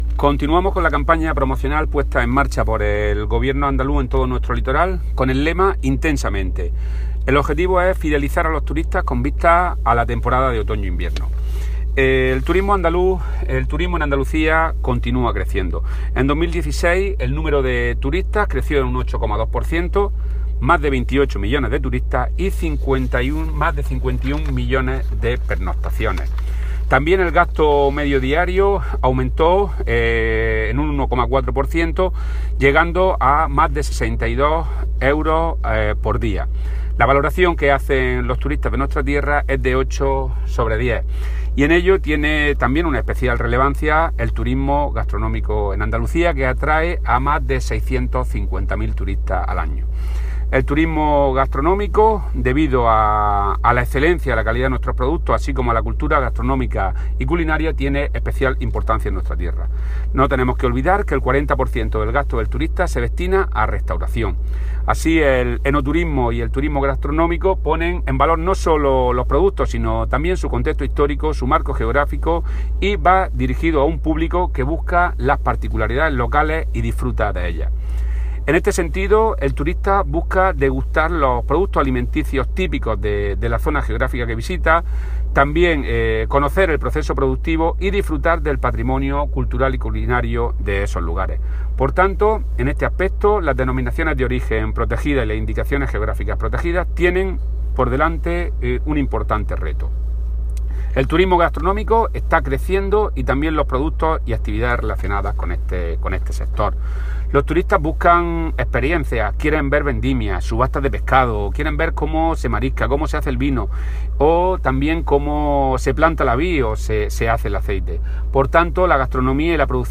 Declaraciones del consejero en la acción promocional en la playa de Vera (Almería).